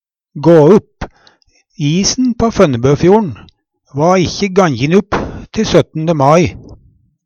gå upp - Numedalsmål (en-US)